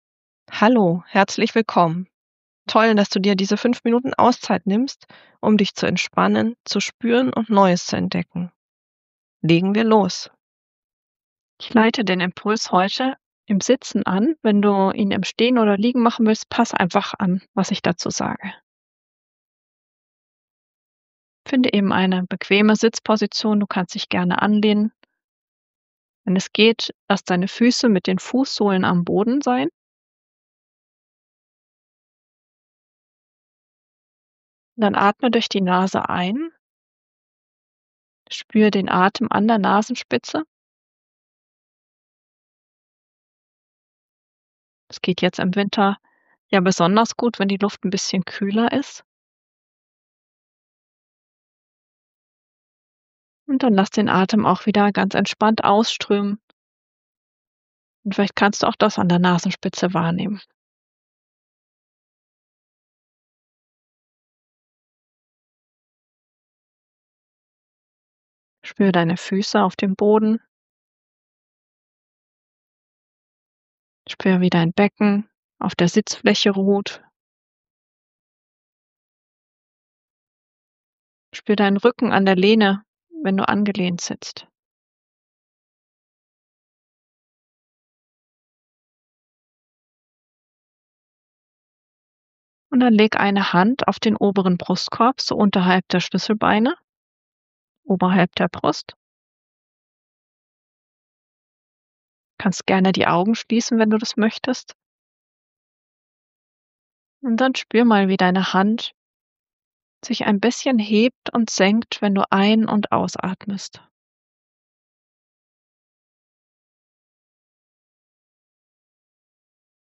Hier geht es zum 5-Minuten-Entspannung-Audio: